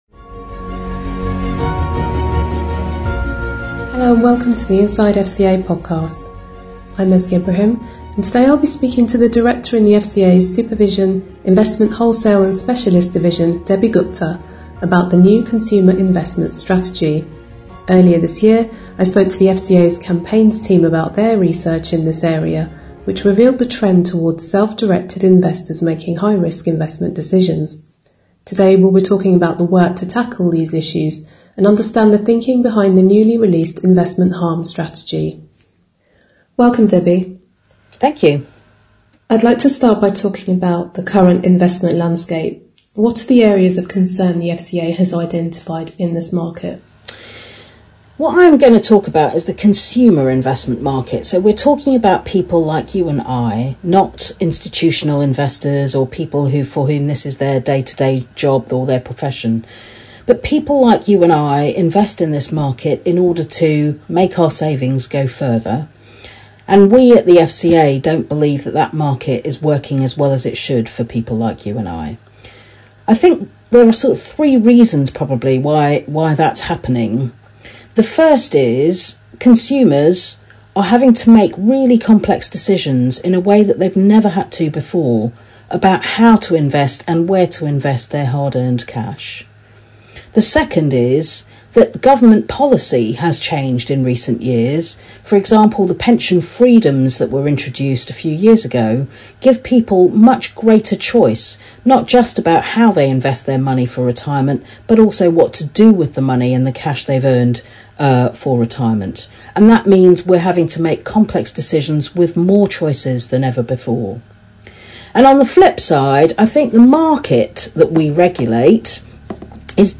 Listen to the Inside FCA Podcast interview